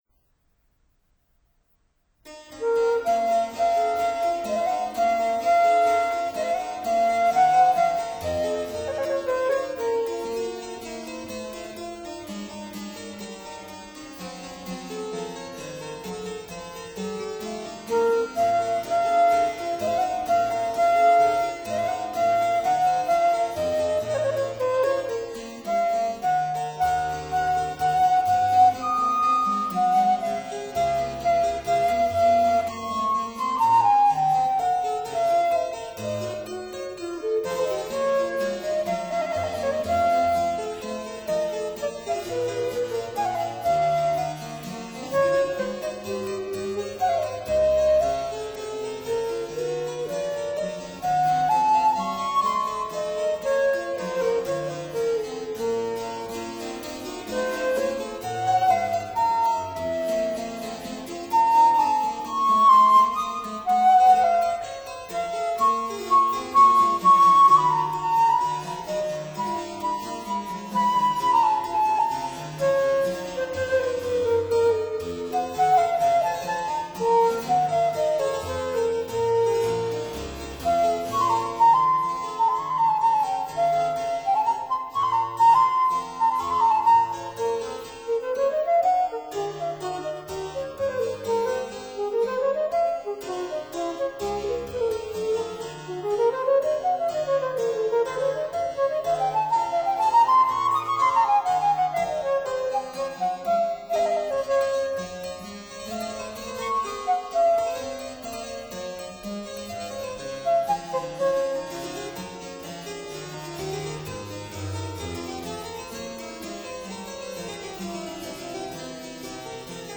(Period Instruments)